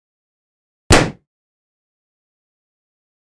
دانلود صدای ترکیدن بادکنک 1 از ساعد نیوز با لینک مستقیم و کیفیت بالا
جلوه های صوتی